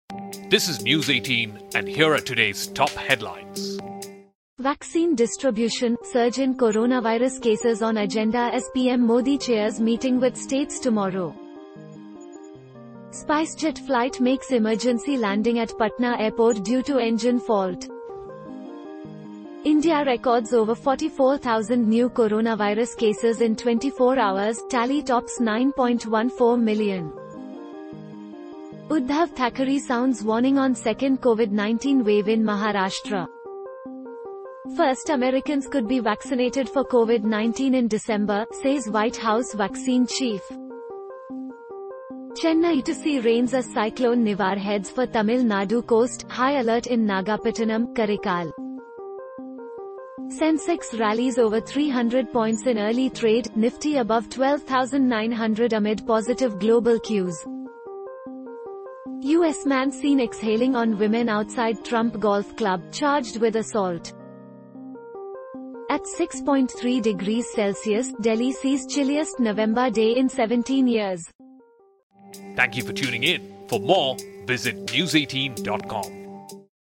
Catch up with the top headlines of the day with our Audio Bulletin, your daily news fix in under 2 minutes.